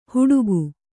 ♪ huḍugu